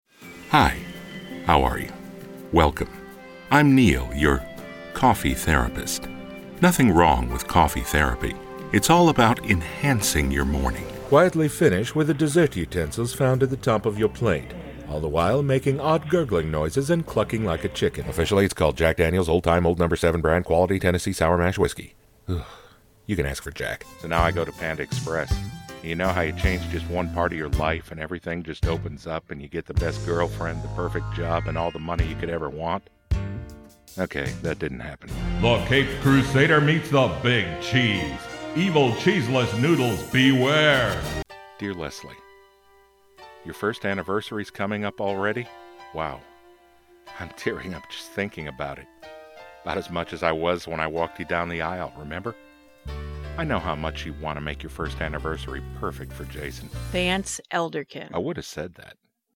A textured male baritone voice to make your script credible.
Sprechprobe: Werbung (Muttersprache):
A textured male baritone voice. Authoritative, warm, friendly, weary...whatever you need.